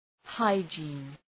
Προφορά
{‘haıdʒi:n}